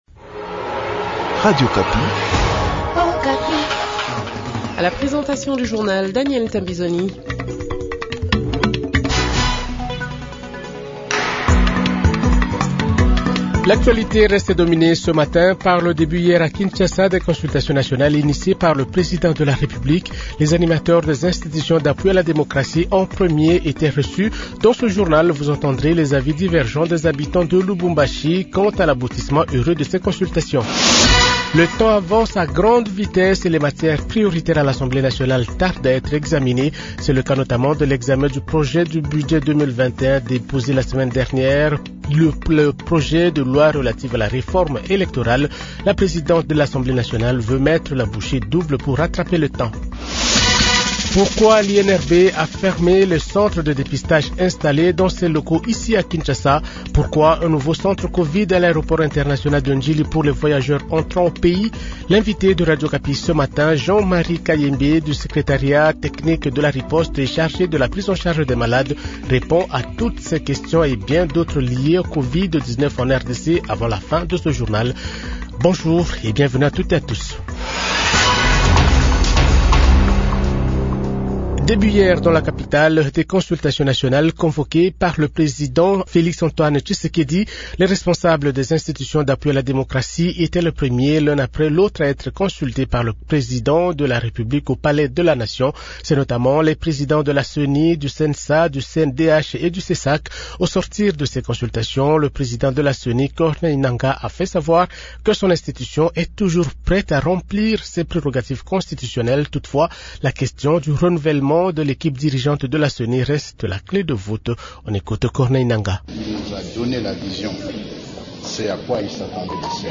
Journal Francais Matin 6h00